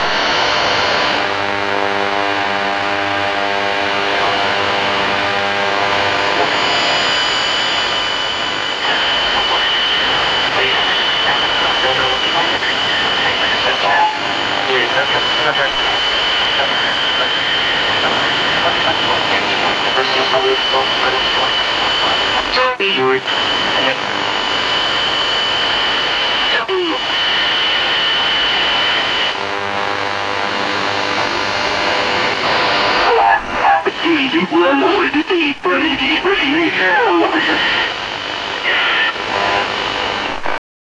Old radio serious news
old-radio-serious-news-qhv44t4k.wav